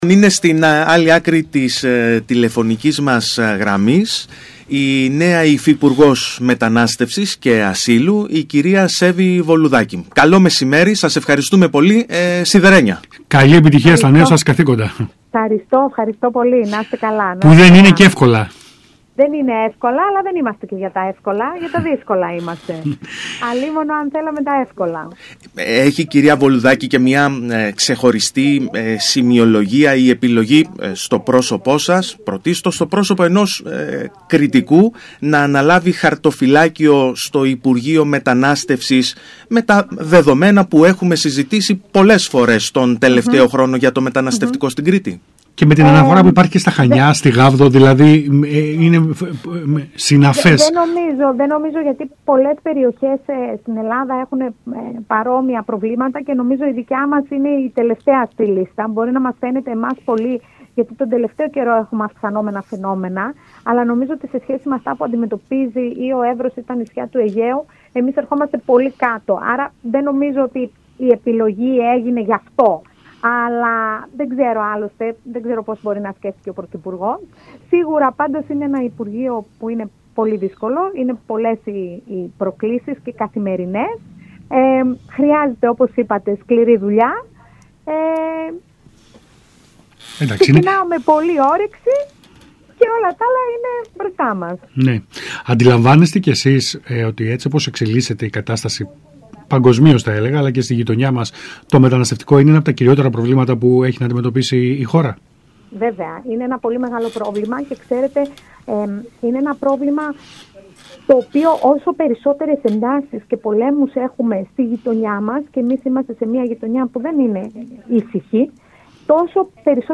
Πανέτοιμη για τις προκλήσεις της κρίσιμης θέσης που αναλαμβάνει δήλωσε η νέα Υφυπουργός Μετανάστευσης Σέβη Βολουδάκη, μιλώντας στον ΣΚΑΙ Κρήτης 92.1 και